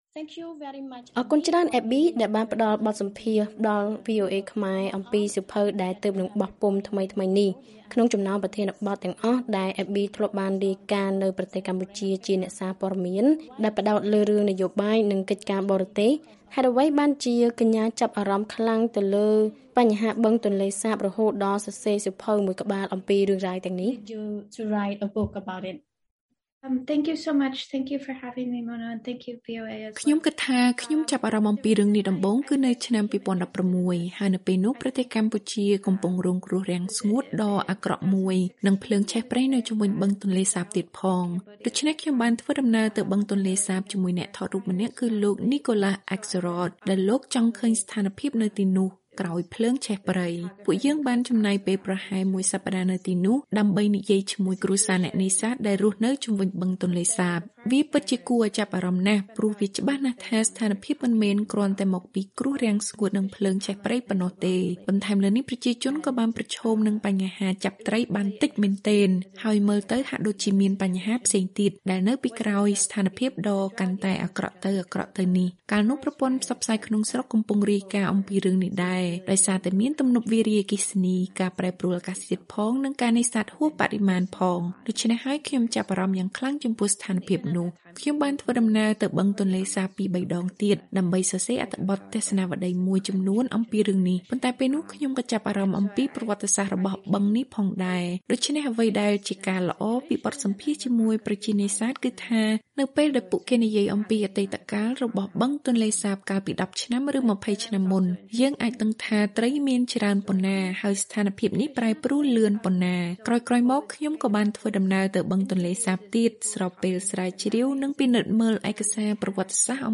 បទសម្ភាសន៍ VOA៖